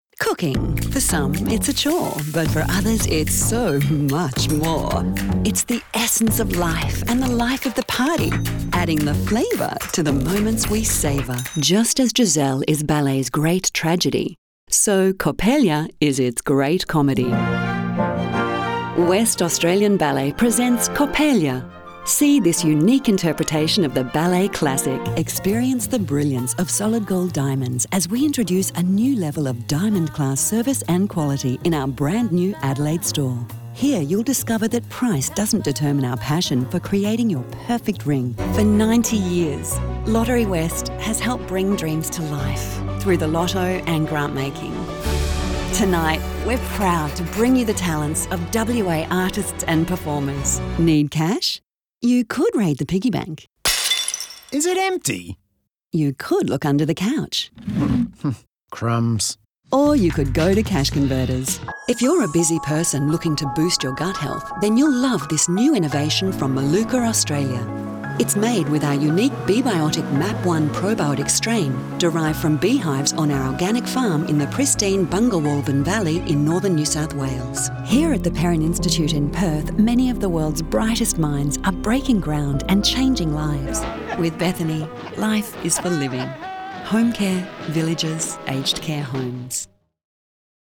a broad vocal range which allows her to transition easily from deeper timbres of sophisticated corporate reads to enthusiastic and bubbly retail, and everything in between
Compilation
VOICEOVER